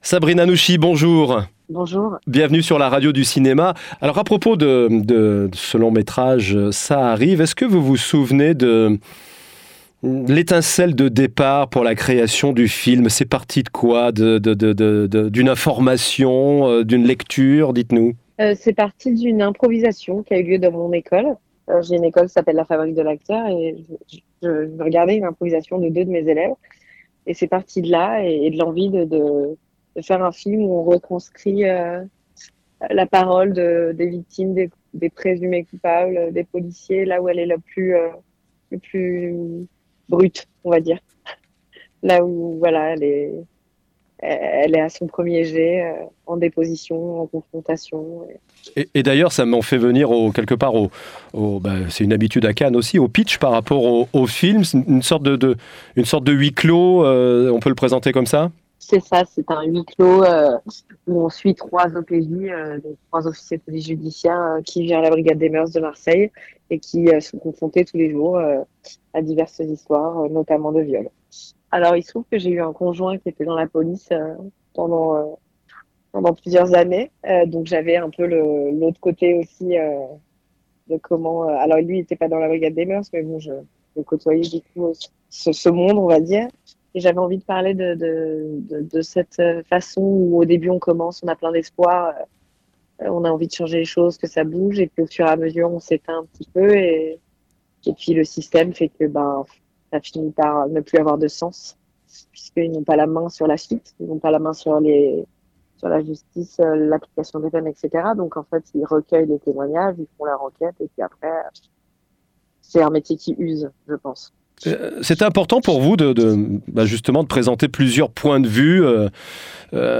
Entretien réalisé dans le cadre du festival de Cannes 2024